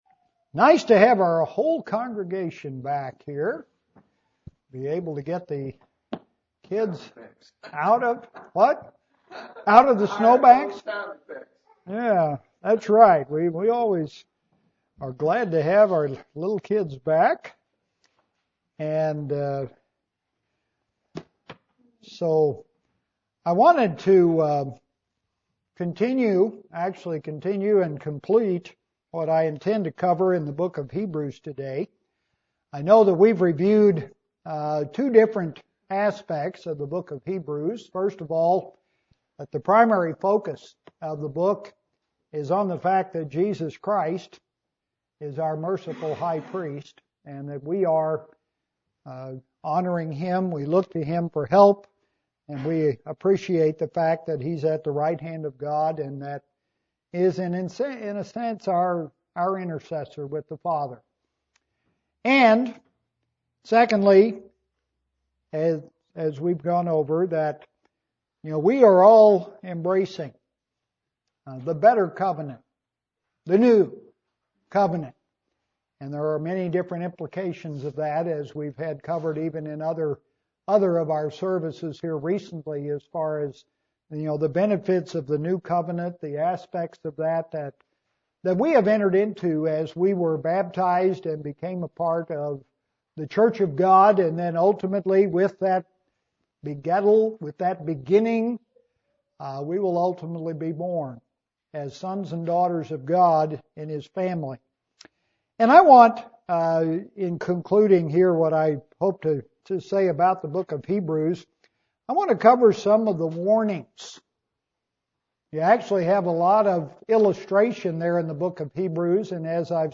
Warnings from the book of Hebrews UCG Sermon Transcript This transcript was generated by AI and may contain errors.